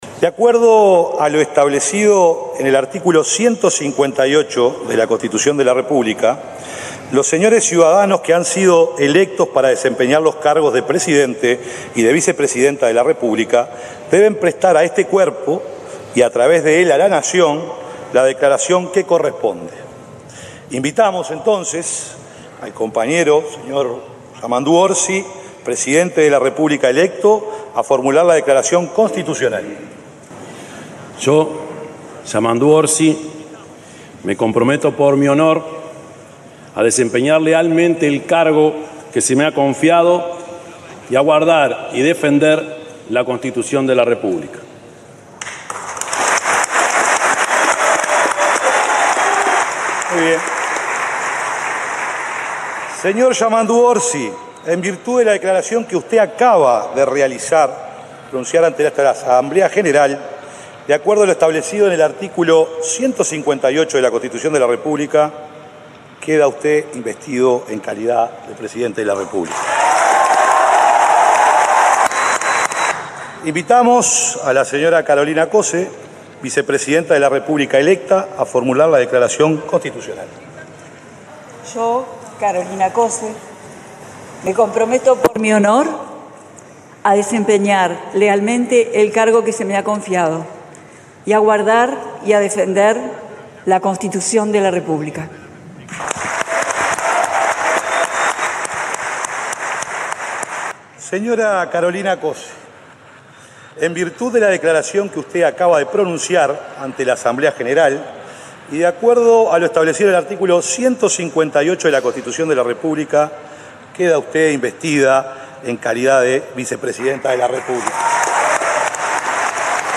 Yamandú Orsi asumió la Presidencia de la República en el Palacio Legislativo, donde realizó el Compromiso de Honor Constitucional. Posteriormente, la vicepresidenta Carolina Cosse también juró en su cargo y, en su rol como presidenta de la Asamblea General, presentó el discurso del mandatario.